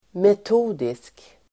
Ladda ner uttalet
Uttal: [met'o:disk]
metodisk.mp3